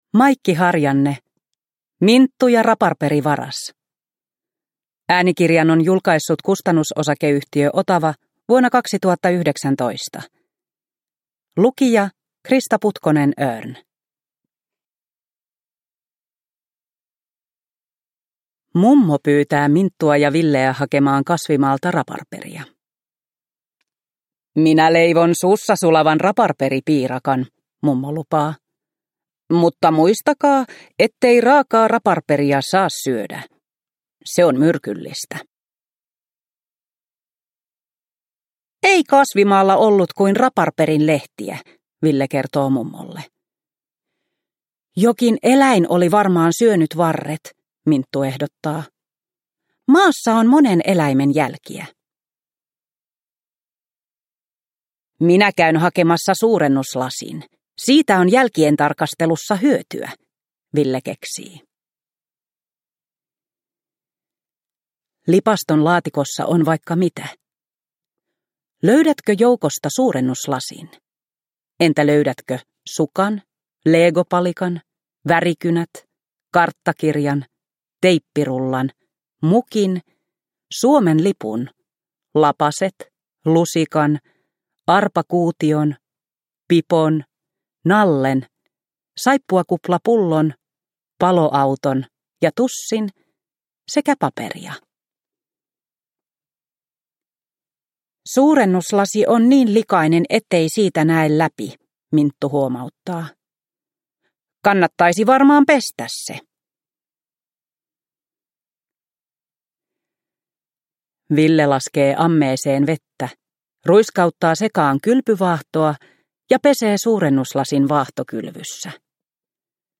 Minttu ja raparperivaras – Ljudbok – Laddas ner